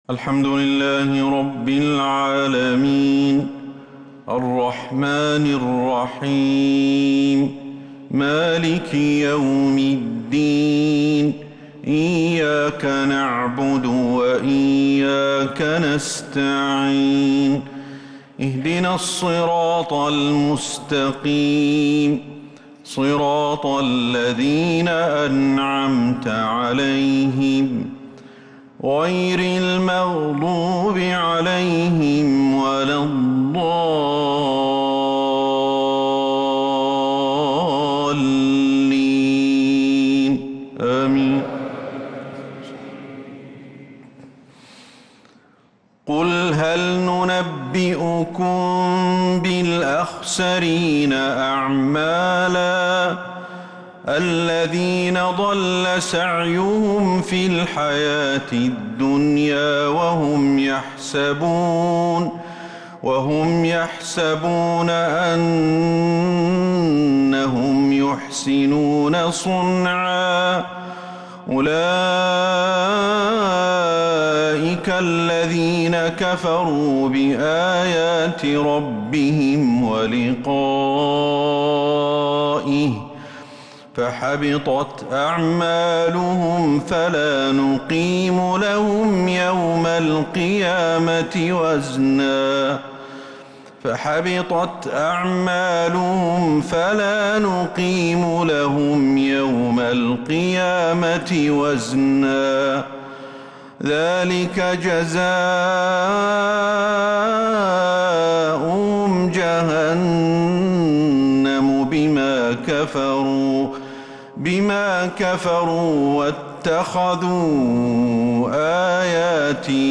عشاء الخميس 7-8-1446هـ من سورتي الكهف 103-110 و مريم 83-95 | Isha prayer from Surah al-Kahf and Maryam 6-2-2025 > 1446 🕌 > الفروض - تلاوات الحرمين